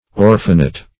Orphanet \Or"phan*et\, n. A little orphan.